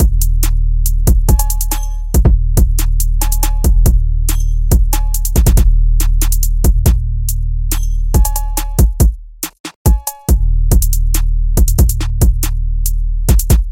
福特全顺 " 福特全顺正在进行中
描述：在一个星期一的早晨，驾驶着福特全顺面包车（2007年制造）穿过小镇。使用索尼PCMD50录音机进行录音。开车离开时给面包车加档。
Tag: 中断 机舱 柴油 驱动器 驾驶 引擎 福特汽车 齿轮 启动 停止 油门 公交 货车